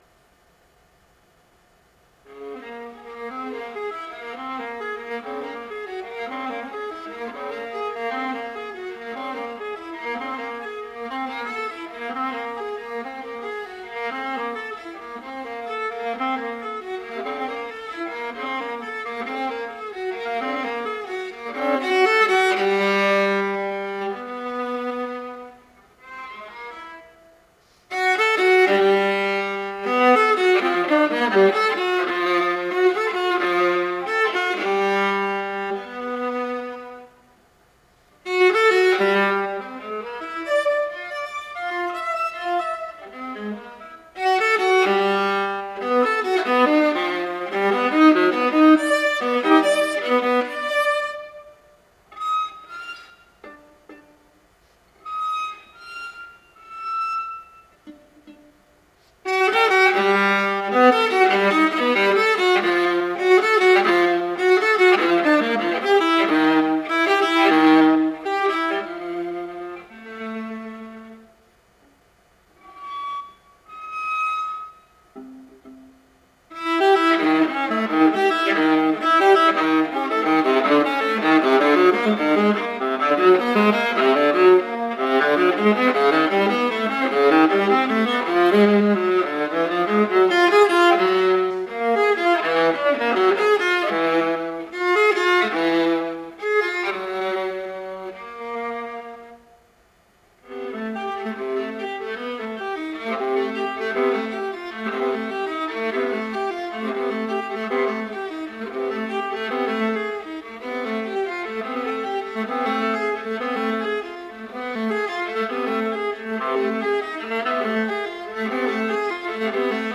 (2008) for viola. 5 minutes.